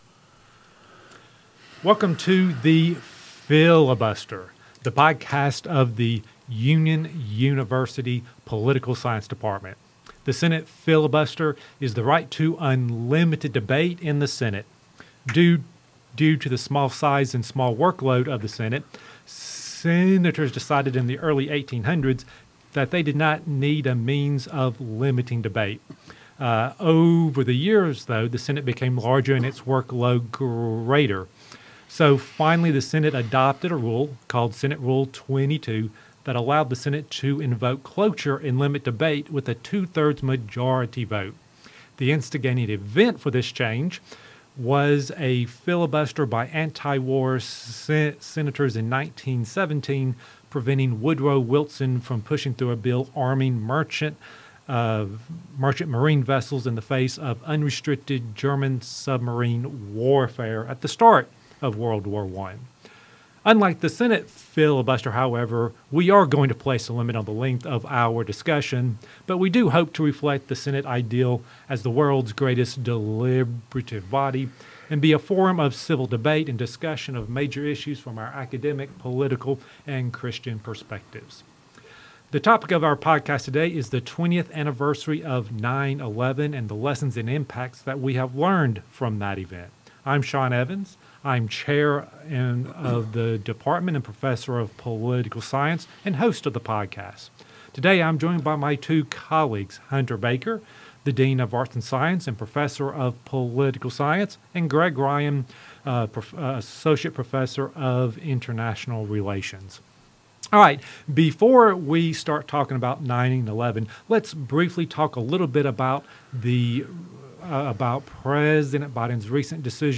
The Political Science faculty briefly discuss the withdrawal from Afghanistan before discussing their memories of 9/11 and the impact and lessons from that tragic day.